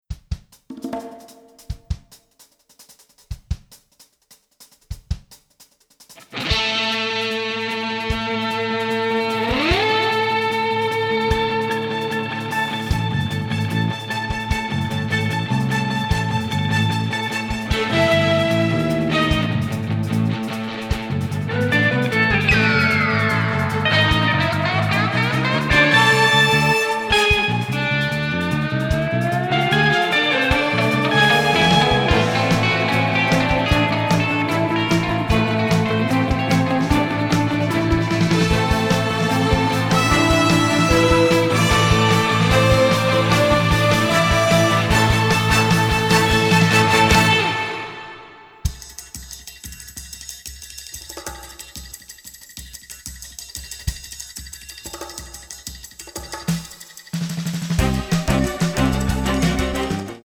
dynamic score, written in a symphonic jazz style
Recorded in London